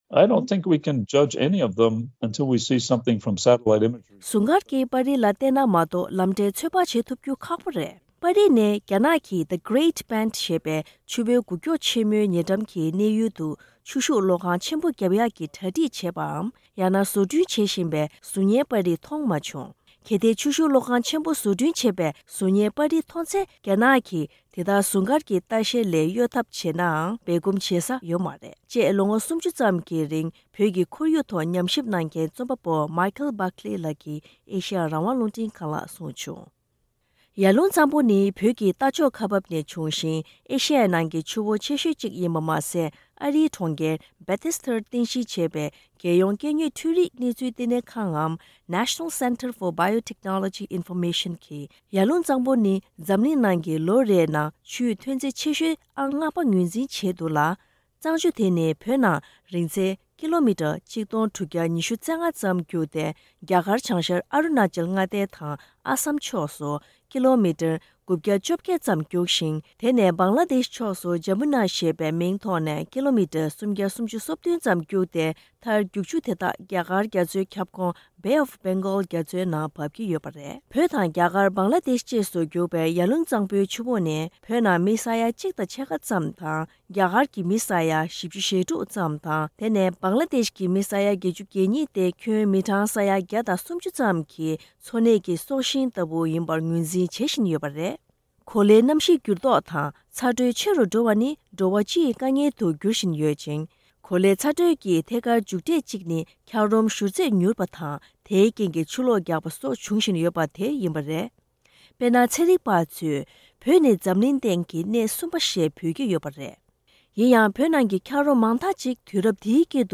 རྒྱ་ནག་གིས་ཡར་ཀླུང་གཙང་པོའི་ཐོག་ཆུ་རགས་རྒྱག་བཞིན་པའི་ཤུགས་རྐྱེན་དང་འབྲེལ་བའི་སྐོར་རྒྱལ་སྤྱིའི་ཁོར་ཡུག་ཆེད་ལས་པ་དང་ཉམས་ཞིབ་པར་བཀའ་འདྲི་ཞུས་པ།